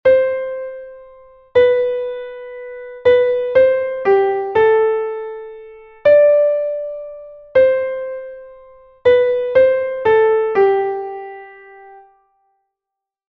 En 3/4